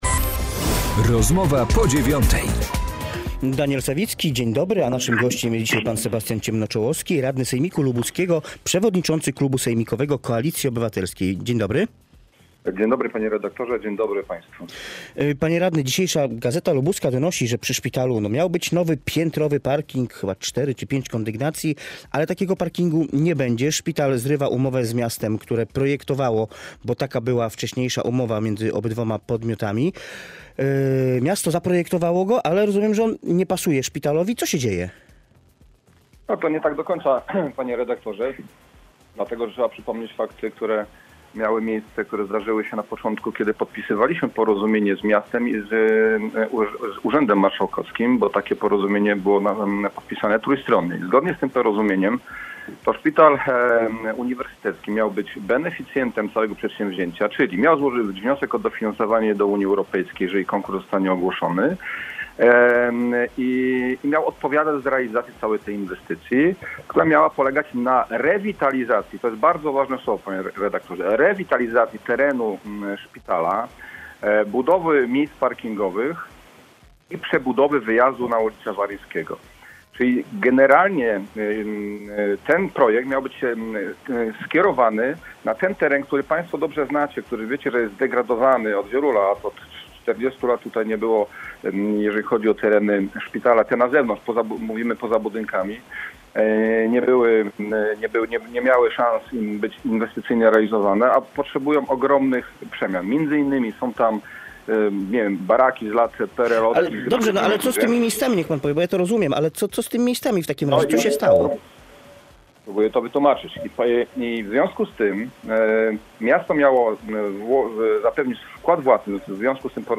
Sebastian Ciemnoczołowski, radny wojewódzki (PO)